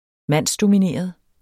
Udtale [ ˈmanˀsdomiˌneˀʌð ]